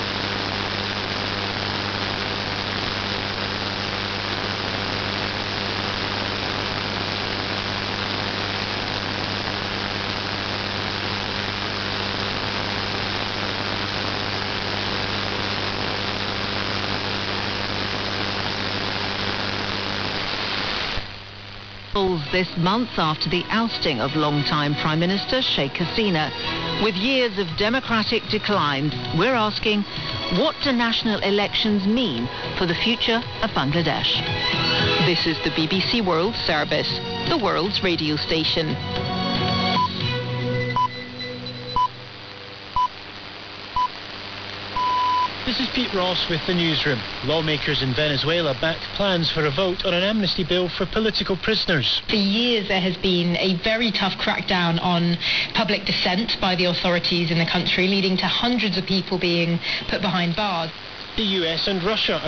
More recently, many of the BBC World Service broadcasts seem to start up very abruptly.  An open carrier appears on the frequency to be used only minutes, or even seconds, before the program (already in progress) begins. One example is noted in this recording made on February 5, 2026, just prior to 2200 UTC sign-on, on a frequency of 11645 kHz.